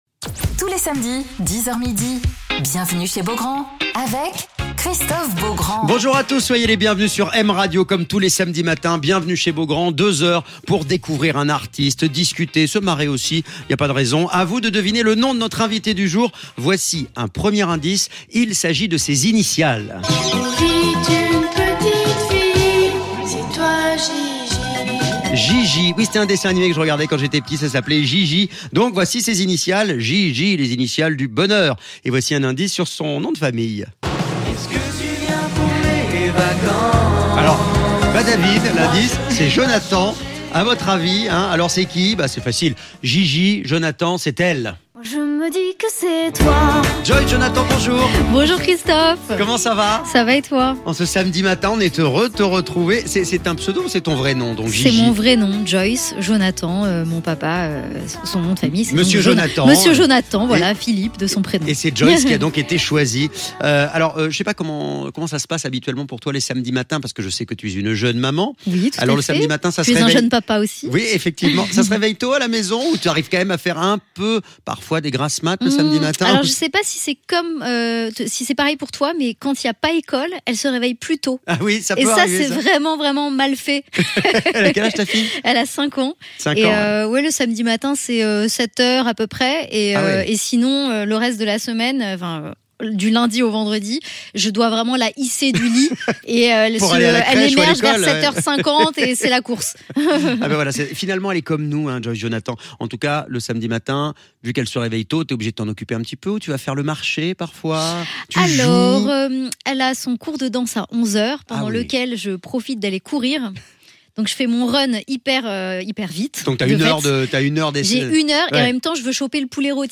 Alors qu'elle prépare un nouvel album pour cet automne, Joyce Jonathan est l'invitée de Christophe Beaugrand sur M Radio !